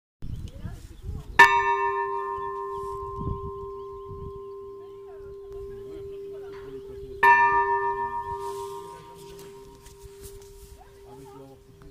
cloche (n°2) - Inventaire Général du Patrimoine Culturel